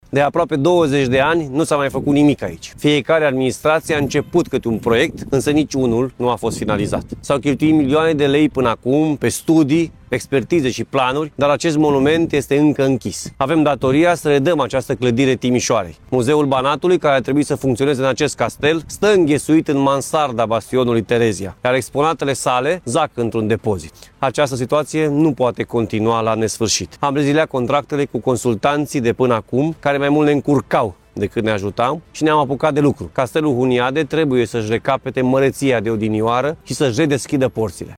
Președintele Consiliului Județean Timiș, Altfed Simonis, spune că anunțul licitației a fost postat deja pe platforma SEAP.